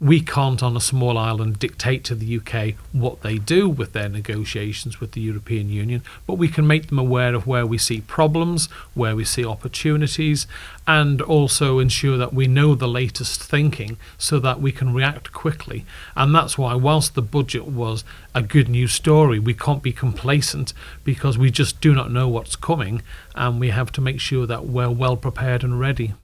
He says it's important to keep up to date with the latest developments: